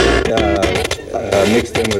120BPMRAD4-L.wav